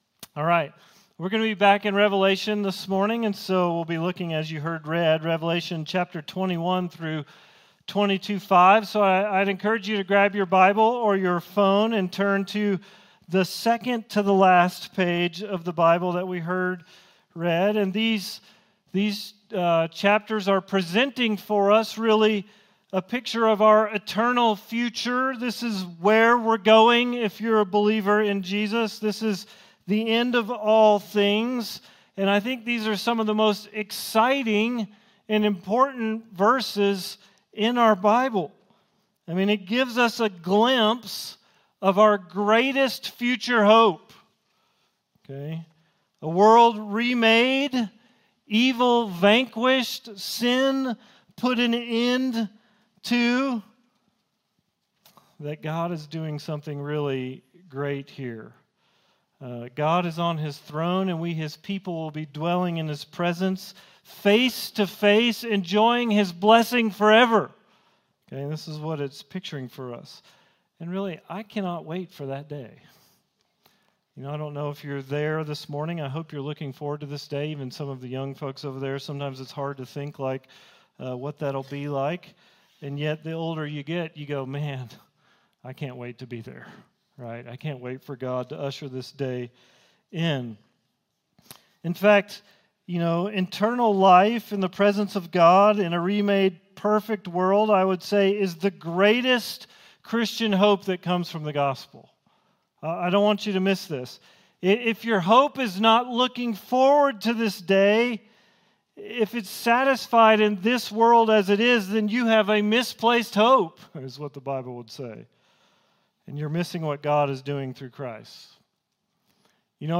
All Sermons - Risen Life Church